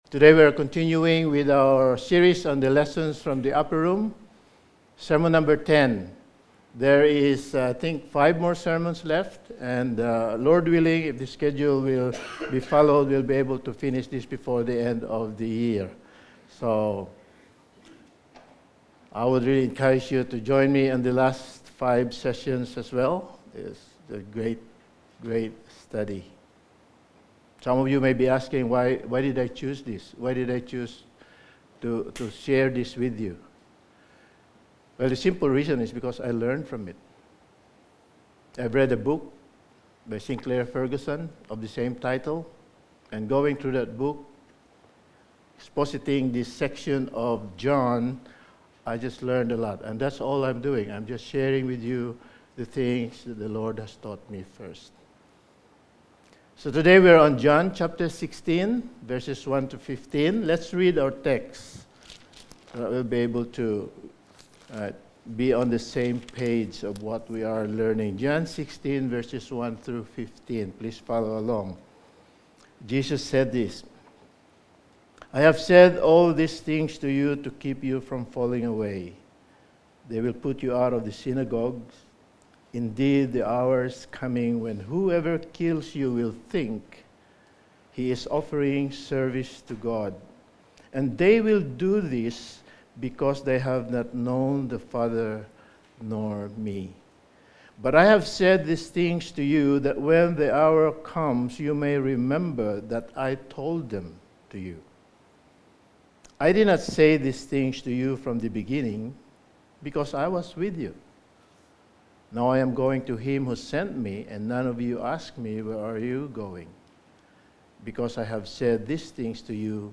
Lessons From the Upper Room Series – Sermon 10: From Admonition to Prediction
Passage: John 16:1-15 Service Type: Sunday Morning